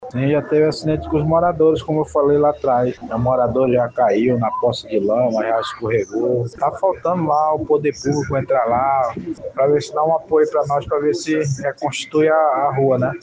SONORA02_MORADOR-.mp3